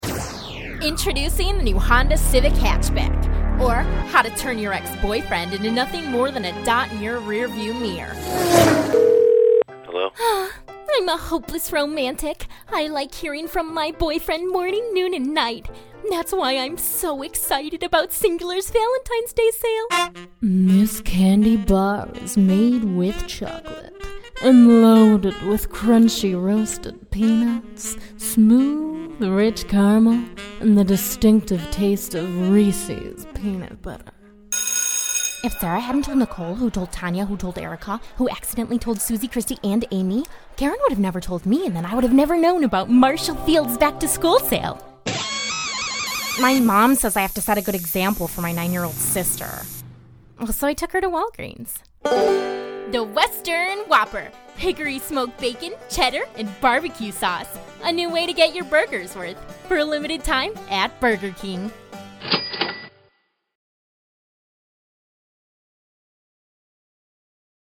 Teen Commercial Voice Over